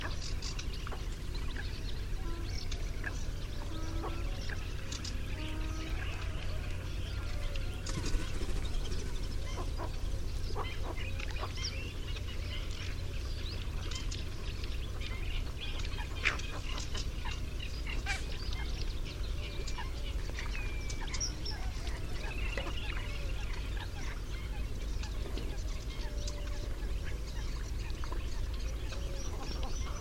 Héron garde-bœufs - Mes zoazos
heron-garde-boeufs.mp3